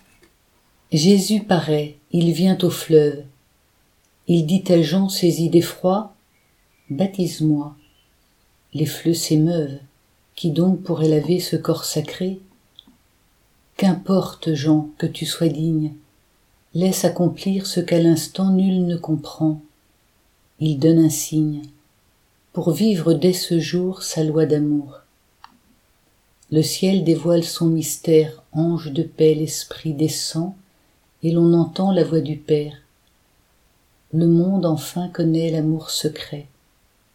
Samedi après l'Epiphanie - Chorale Paroissiale du Pôle Missionnaire de Fontainebleau
Hymne-Jesus-parait-Il-vient-au-fleuve.mp3